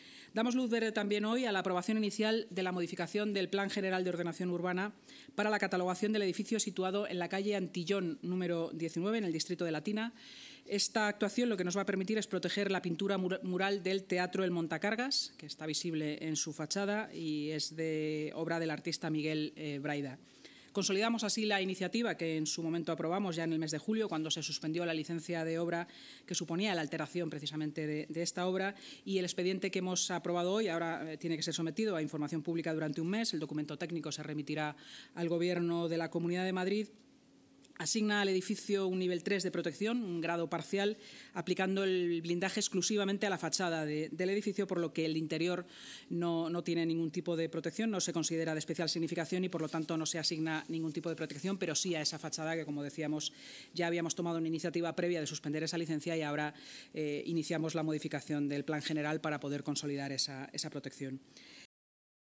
Esta actuación permitirá proteger la pintura mural del edificio del Teatro El Montacargas, visible en su fachada, obra del artista Miguel Brayda. Así lo ha anunciado en rueda de prensa la vicealcaldesa y portavoz municipal, Inma Sanz.